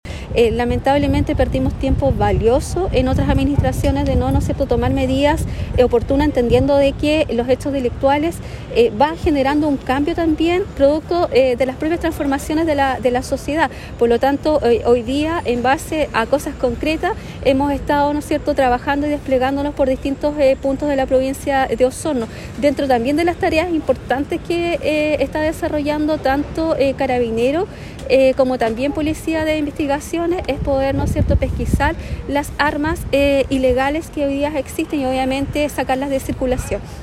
Desde la Delegación Presidencial Provincial de Osorno, se enfatizó que se está realizando un trabajo exhaustivo con los distintos municipios para fortalecer el trabajo de seguridad, como lo explicó la Delegada Claudia Pailalef.